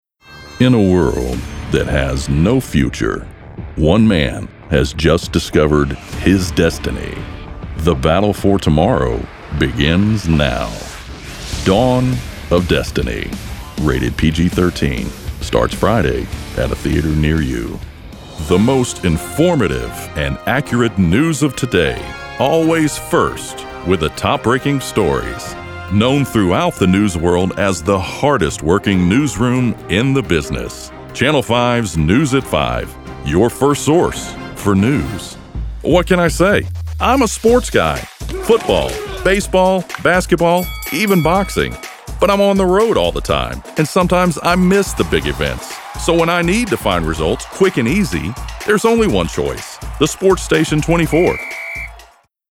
A smooth and persuasive voice to help promote your product and engage your audience.
Southern, Western
Middle Aged